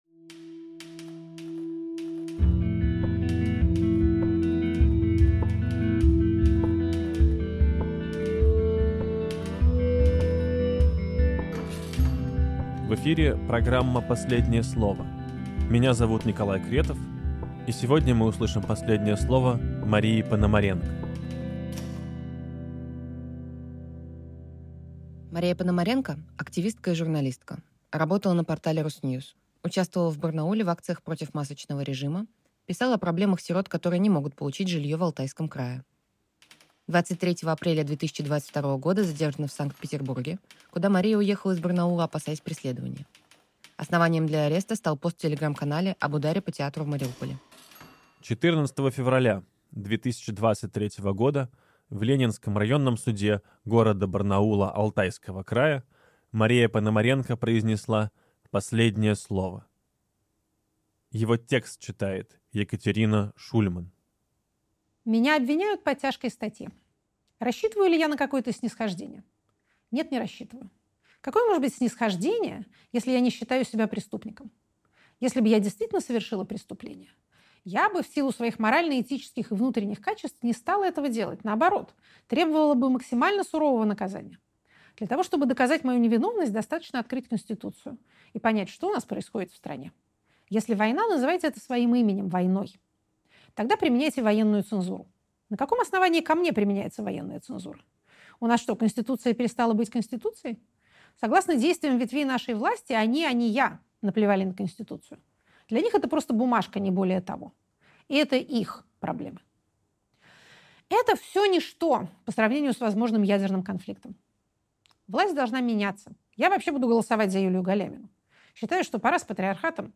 Екатерина Шульманполитолог
Текст читает Екатерина Шульман.